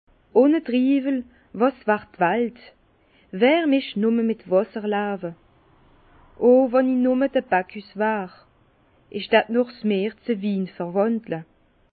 Bas Rhin
Ville Prononciation 67
Herrlisheim